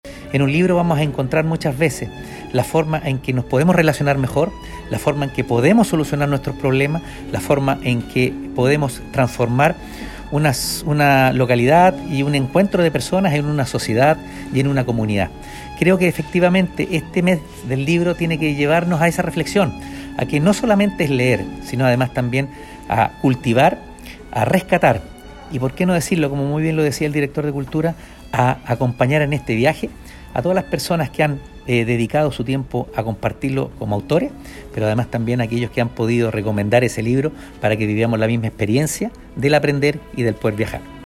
“Es importante que le tomemos el valor al mes del libro, no solamente por el hecho de leer, sino que también para cultivar y rescatar algo que cada autor nos entrega mediante su obra, llevándonos a un viaje y al mismo tiempo poder aprender”, señaló Óscar Calderón Sánchez, alcalde de Quillota, quien fue el encargado de cerrar la ceremonia.
Cuna-alcalde-Lanzamiento-mes-del-libro-1.mp3